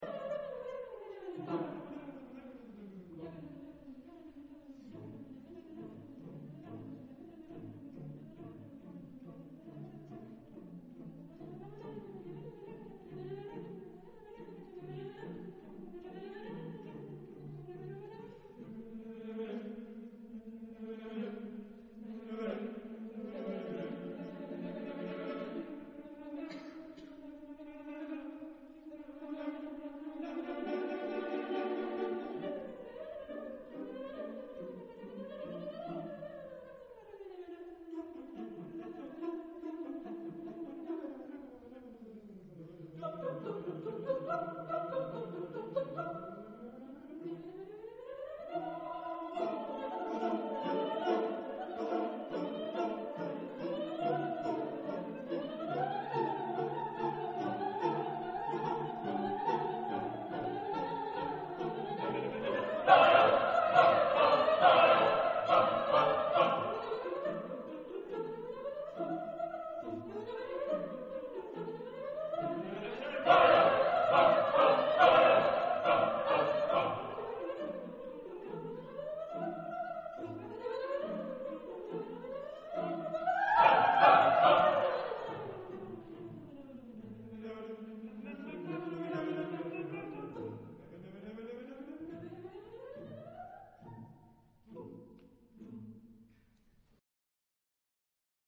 Type de choeur : SSAATTBB  (8 voix mixtes )
Tonalité : la mineur